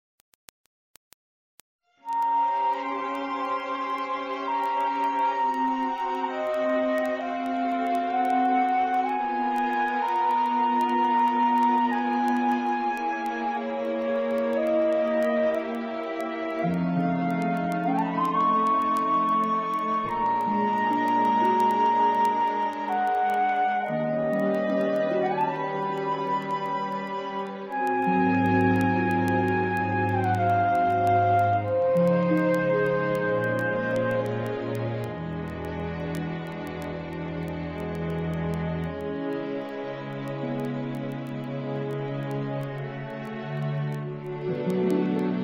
NOTE: Background Tracks 10 Thru 18